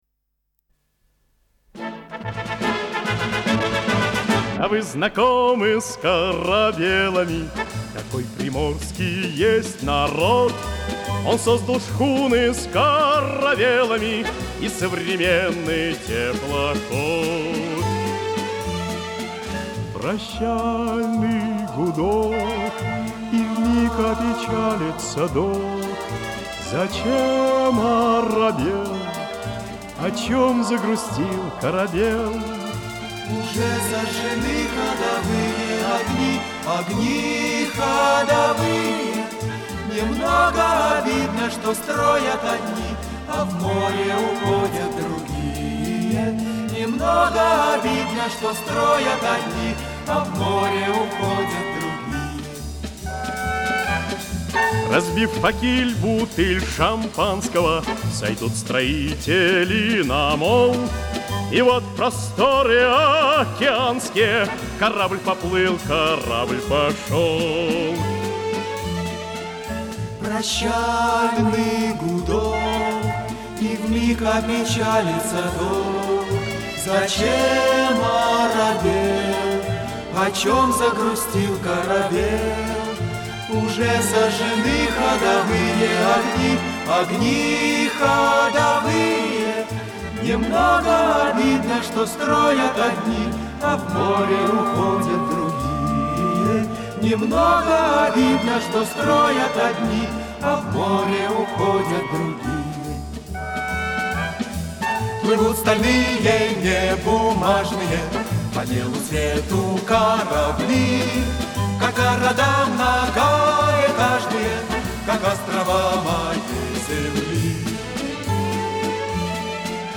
саксофон